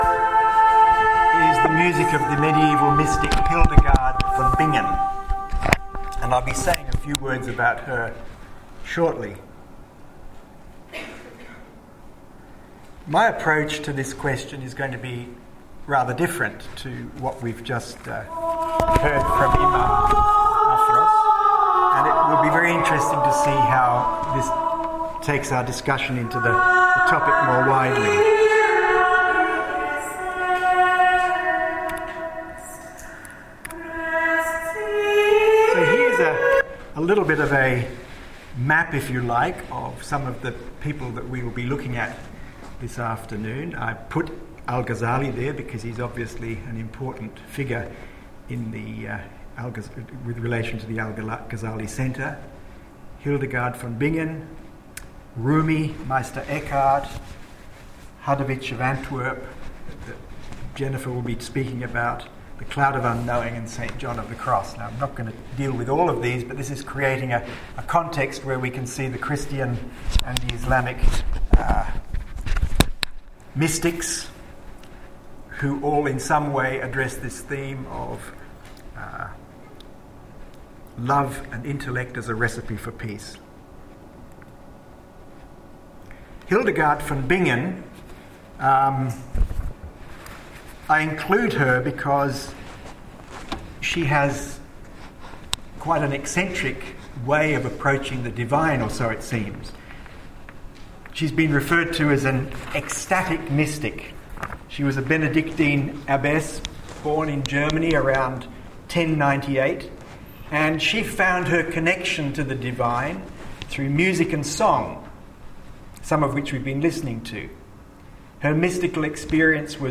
I was privileged to be able to organize with the Al Ghazzali Centre in Sydney a Symposium on the theme of Love & Intellect: A Recipe for Peace.